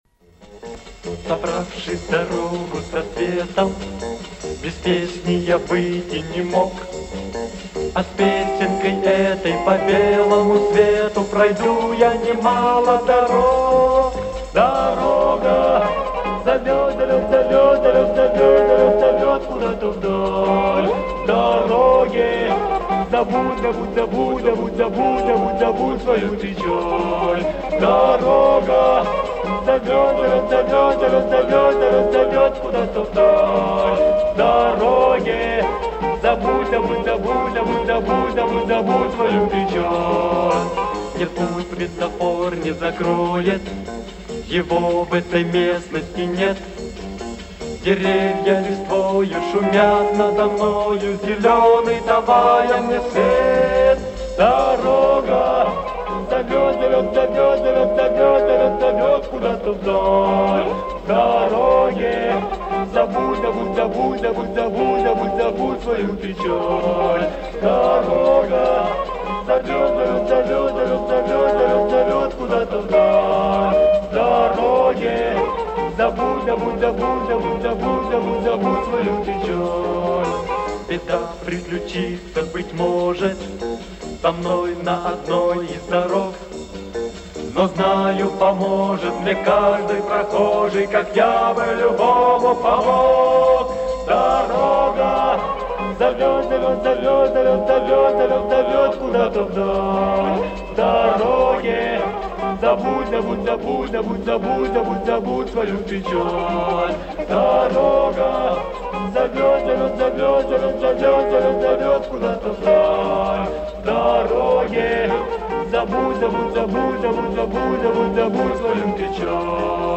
Записи эфирные с радио